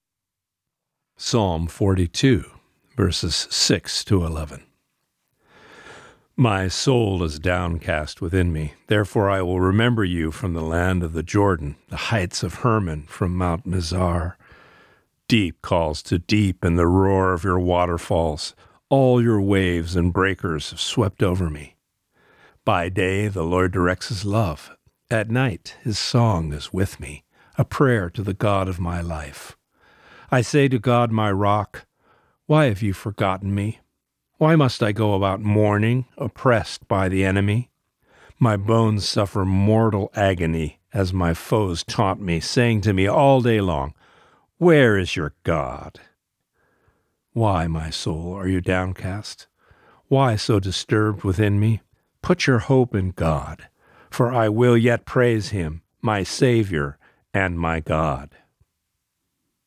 Reading: Psalm 42:6-11